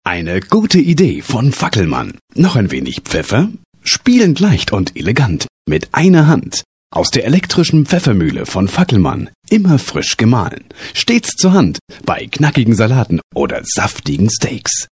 Ausgebildeter Sprecher und Moderator TV Rundfunk Kino Multimedia
Sprechprobe: Werbung (Muttersprache):
voice over german